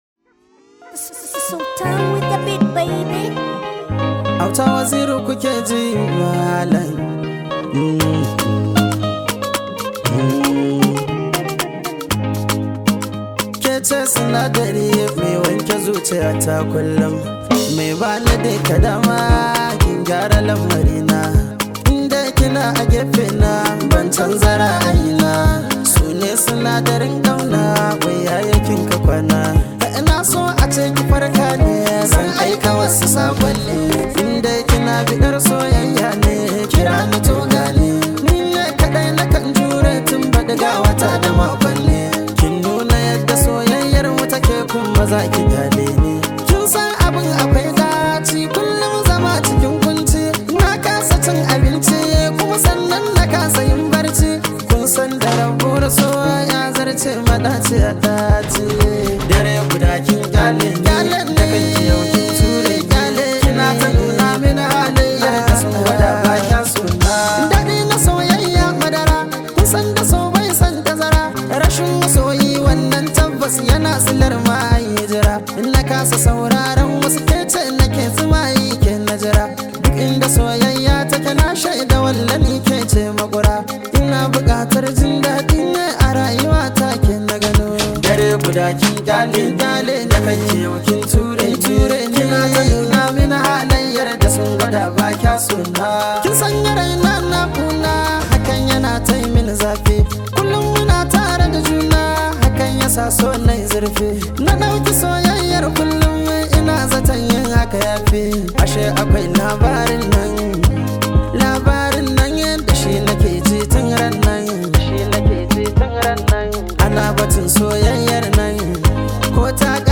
Hausa Musics
matashin mawaki wanda yayi fice sosai a wajen rera wakoki.